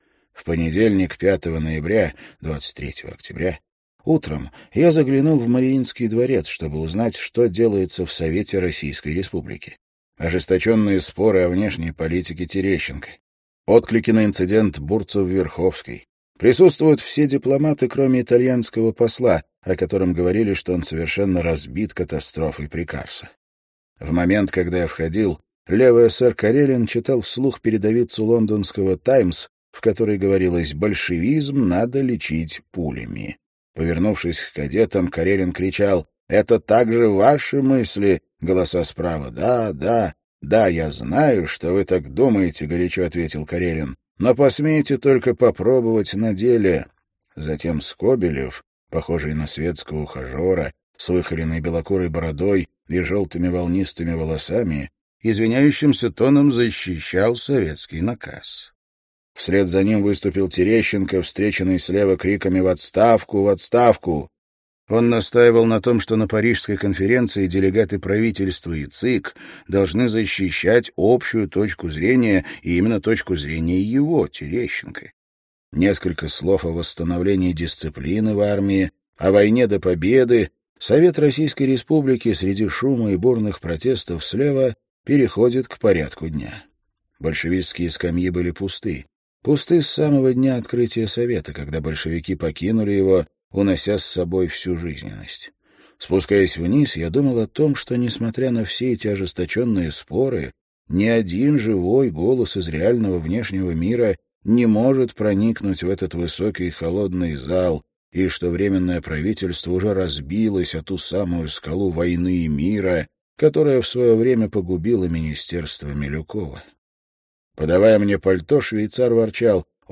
Аудиокнига Десять дней, которые потрясли мир | Библиотека аудиокниг